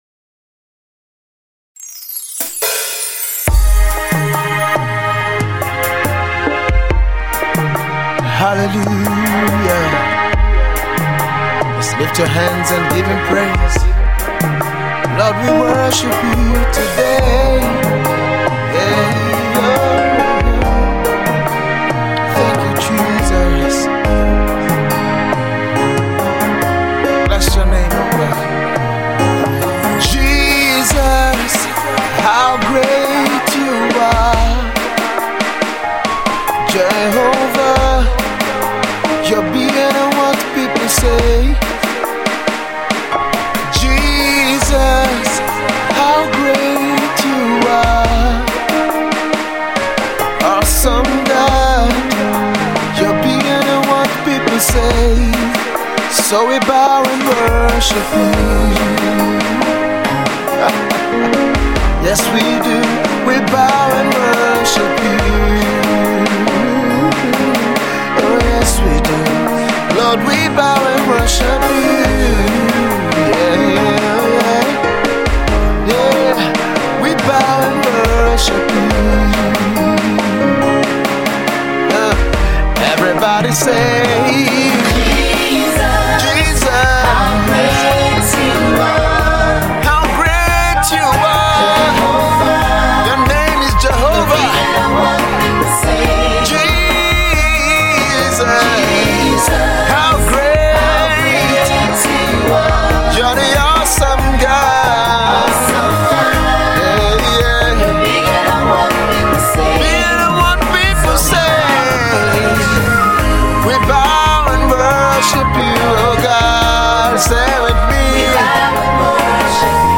Gospel
worship song